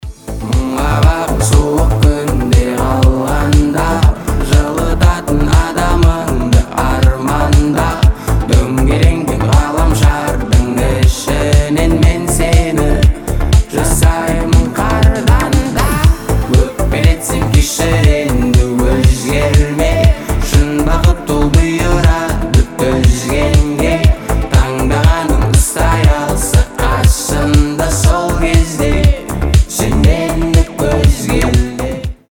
• Качество: 320, Stereo
мужской голос